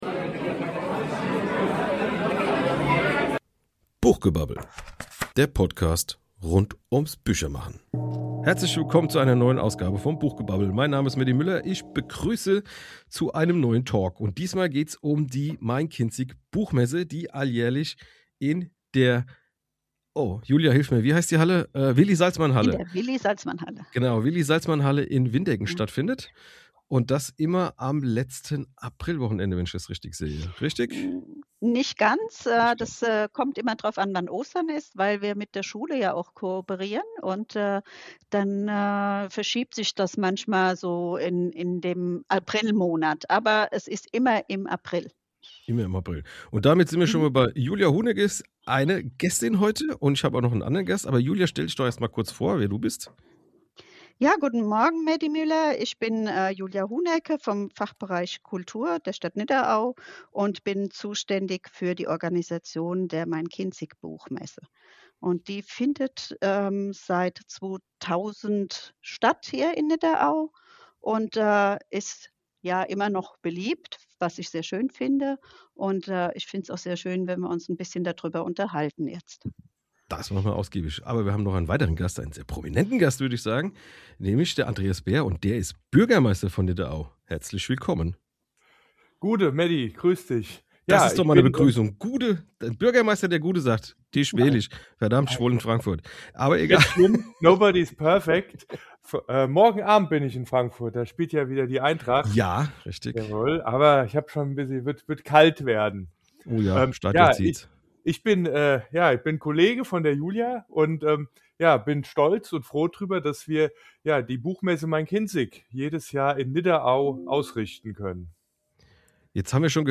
in dieser Folge spreche ich gleich mit zwei Gästen über die Buchmesse Main Kinzig. Diese Messe ist der Treffpunkt der Literaturszene im Rhein Main Gebiet.